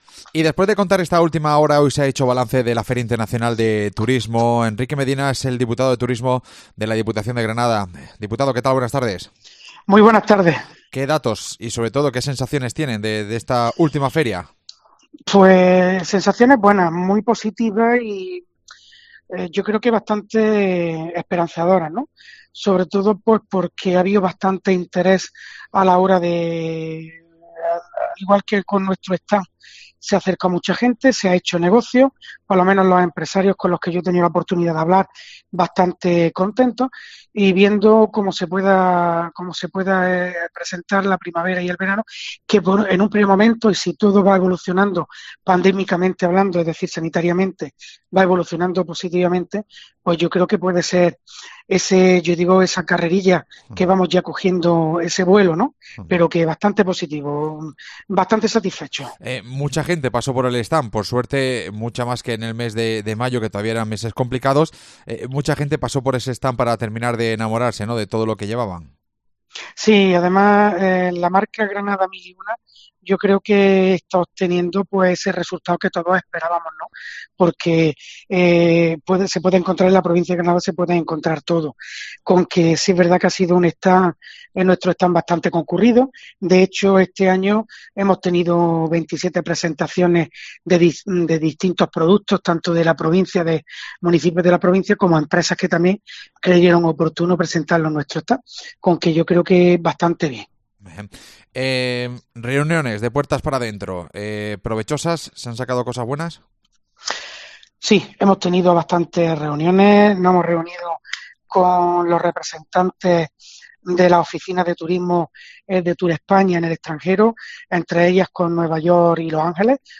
AUDIO: El diputado de turismo, Enrique Medina, ha hecho balance de la última edición de FITUR